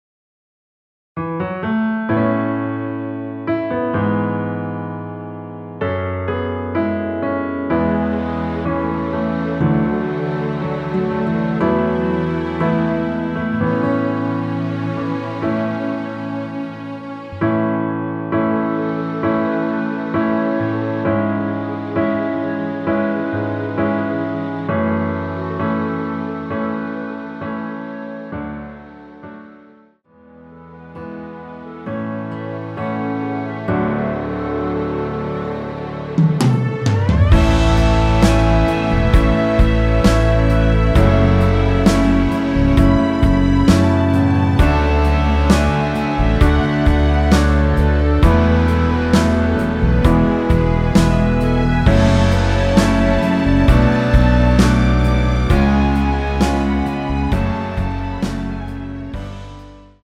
원키 멜로디 포함된 MR입니다.
멜로디 MR이라고 합니다.
앞부분30초, 뒷부분30초씩 편집해서 올려 드리고 있습니다.
중간에 음이 끈어지고 다시 나오는 이유는